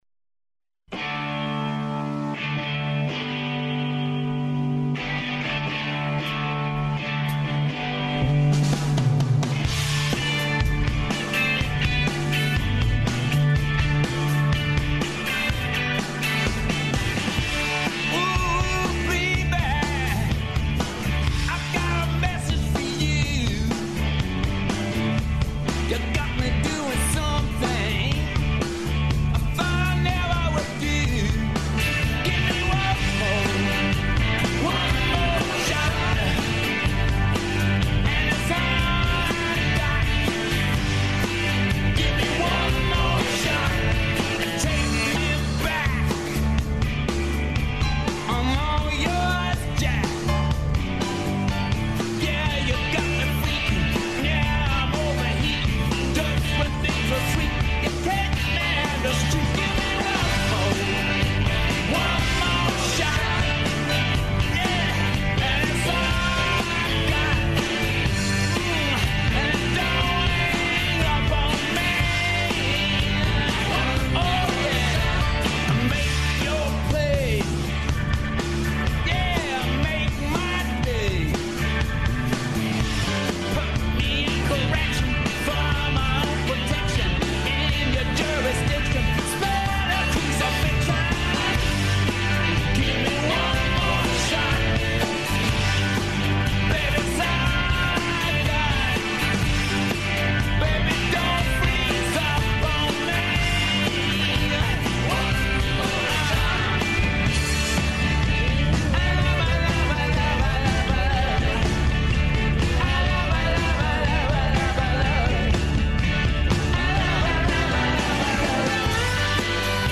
Данас учимо: како да сами направите трибину од идеје до реализације. У томе ће нам помоћи људи из институција, са независне сцене и појединци са искуством.
преузми : 20.17 MB Индекс Autor: Београд 202 ''Индекс'' је динамична студентска емисија коју реализују најмлађи новинари Двестадвојке.